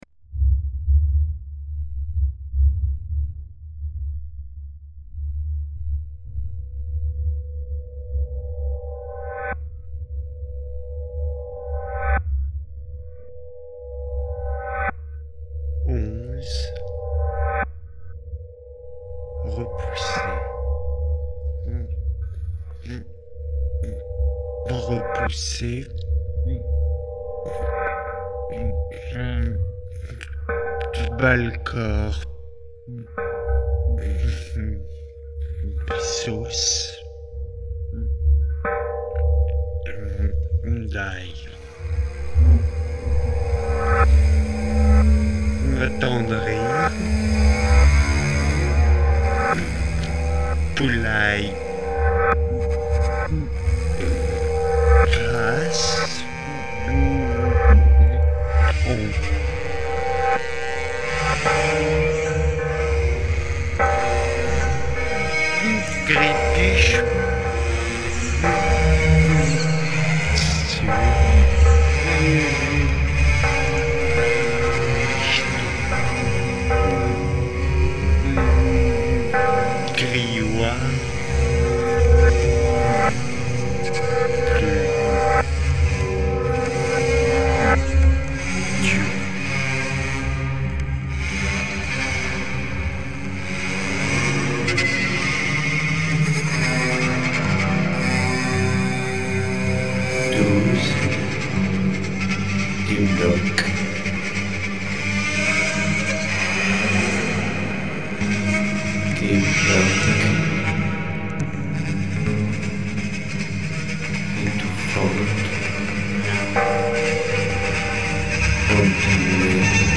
J'en ai enregistré des lectures et je m'en suis servi comme matière première pour travailler.